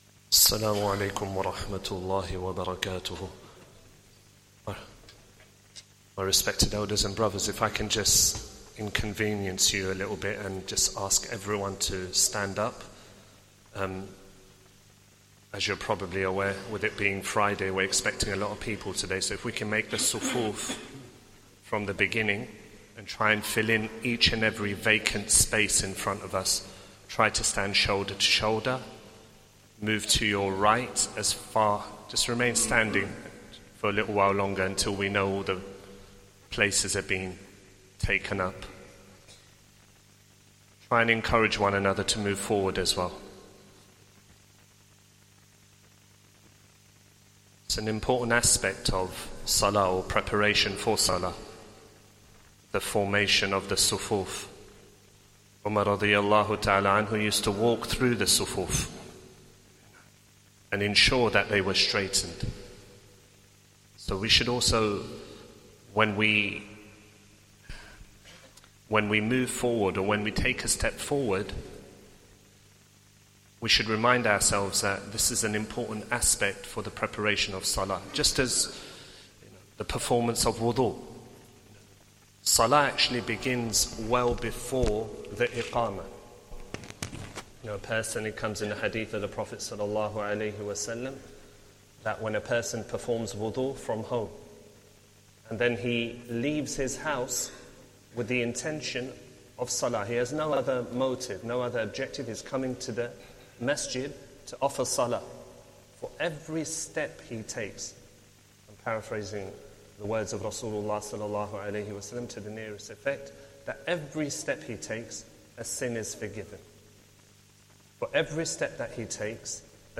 Jummah Talk and 1st Khutbah